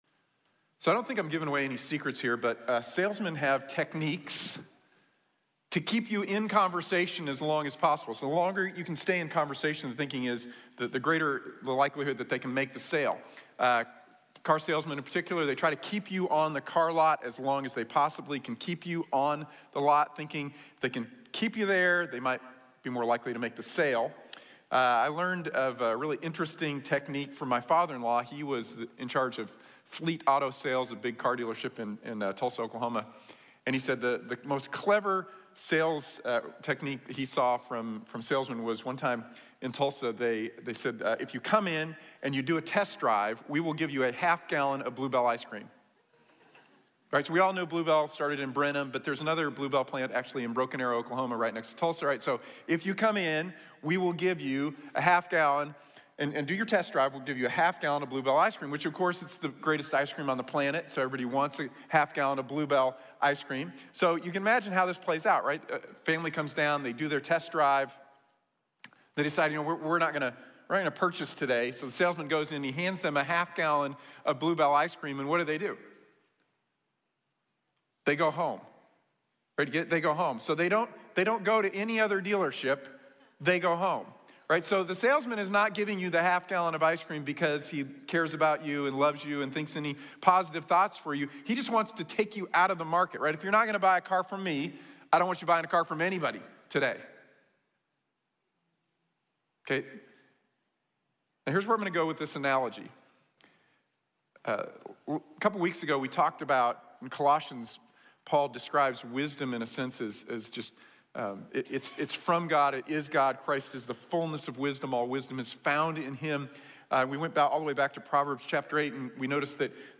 Sólo Jesús | Sermón | Iglesia Bíblica de la Gracia